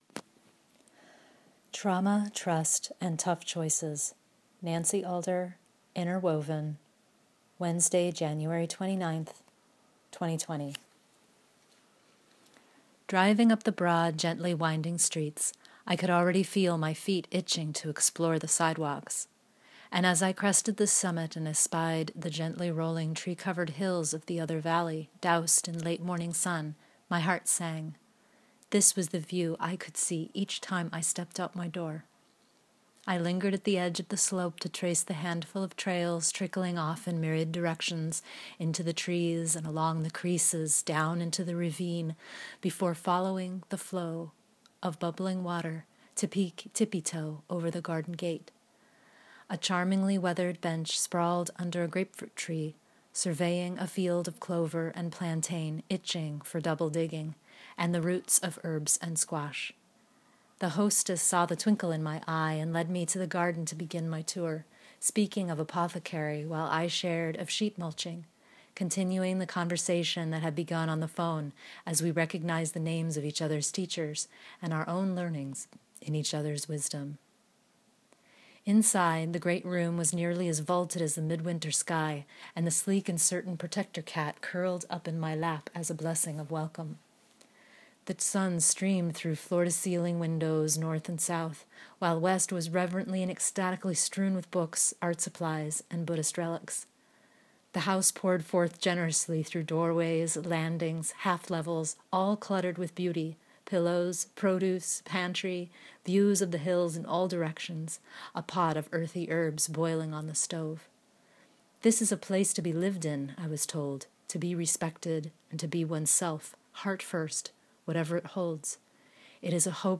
Let me read to you!